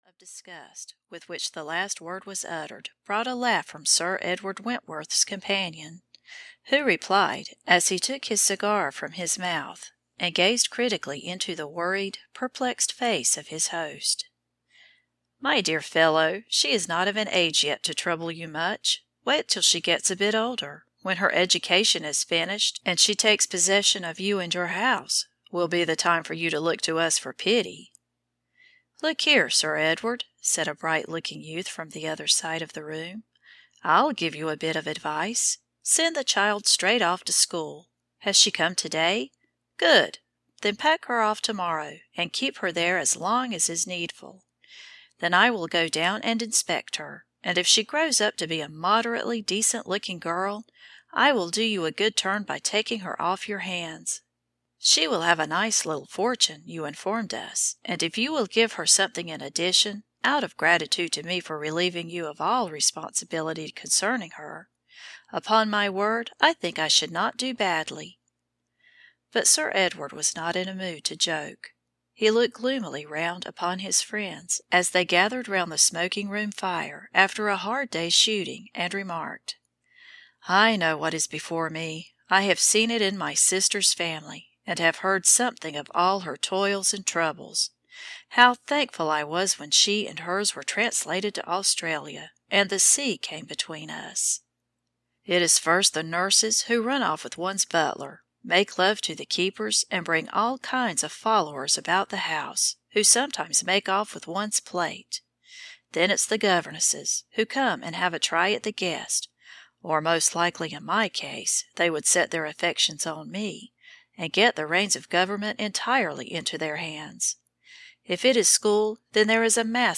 Probable Sons (EN) audiokniha
Ukázka z knihy